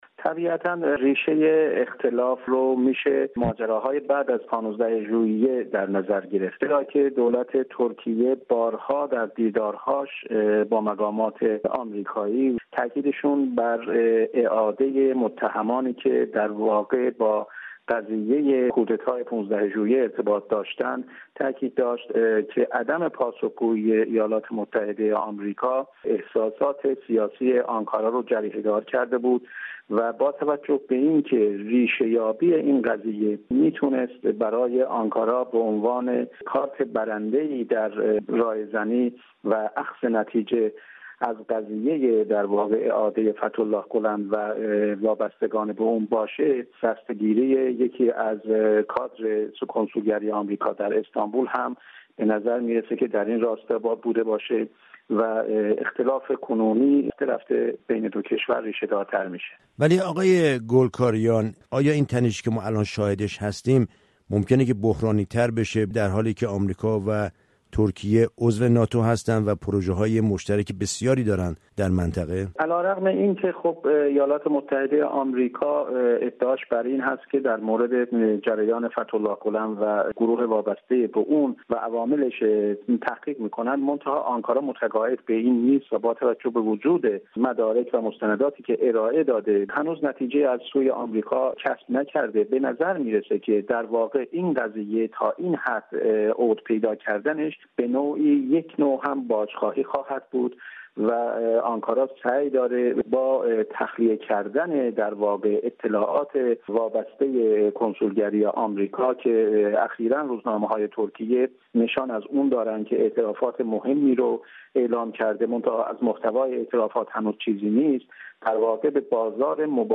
با دستگیری یکی از کارمندان کنسولگری آمریکا در استانبول به اتهام ارتباط با گولن که اپوزیسیون مخالف دولت ترکیه محسوب می شود و متعاقب آن اظهارات سفیر آمریکا در آنکارا موجب بالا گرفتن تنش میان دو کشور آمریکا و آنکارا شده است. گفتگوی رادیو فردا